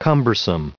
added pronounciation and merriam webster audio
1127_cumbersome.ogg